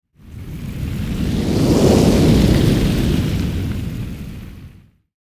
Download Fireball sound effect for free.